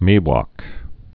(mēwŏk)